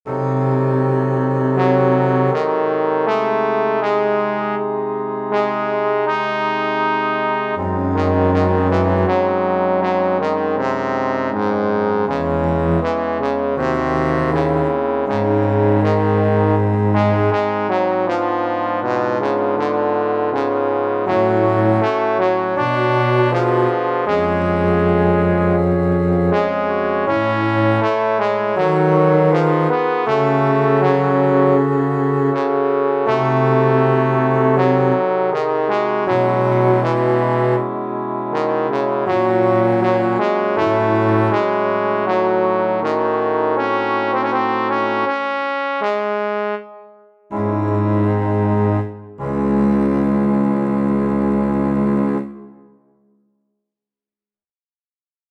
This will be movement 4 of my cantata (WIP). Text taken from Prometheus.